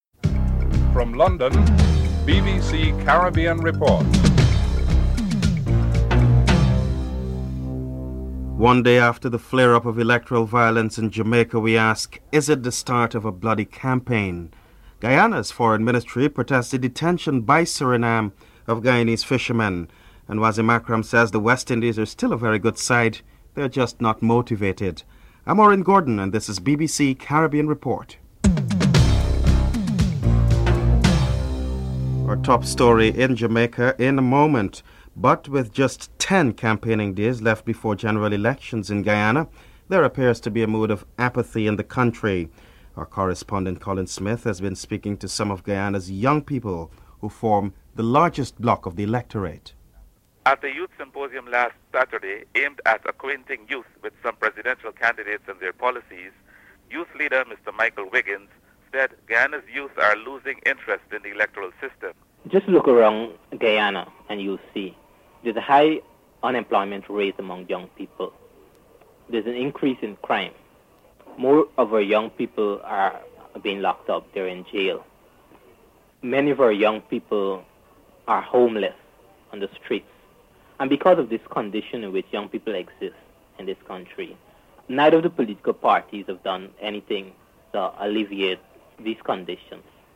1. Headlines (00:00-00:31)